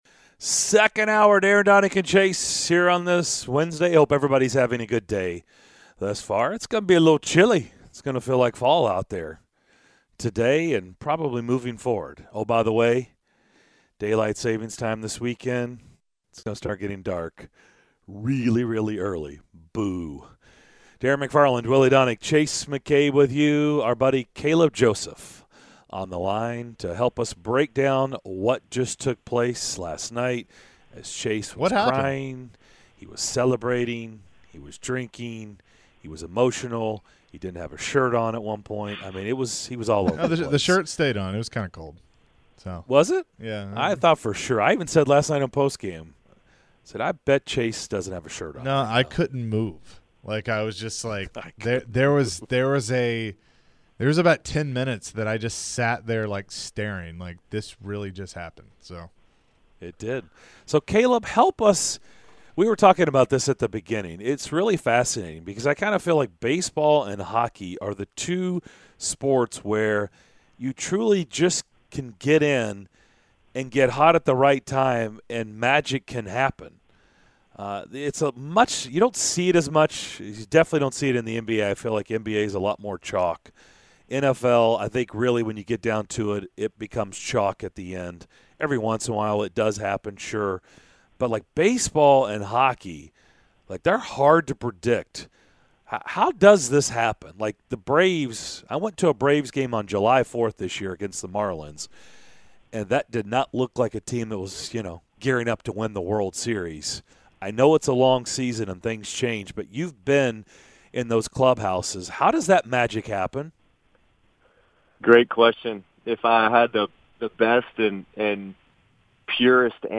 MLB Catcher Caleb Joseph joined the DDC to give his thoughts on the Braves winning the World Series!